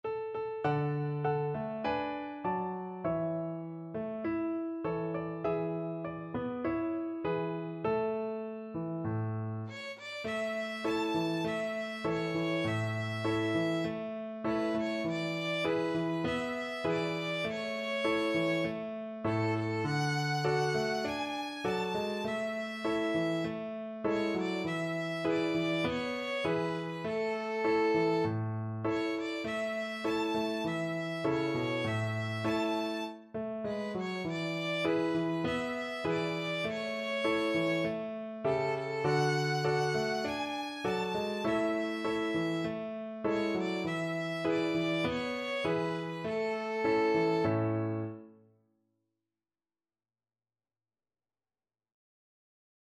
Violin
A major (Sounding Pitch) (View more A major Music for Violin )
Moderato
4/4 (View more 4/4 Music)
Traditional (View more Traditional Violin Music)
balonku_ada_lima_VLN.mp3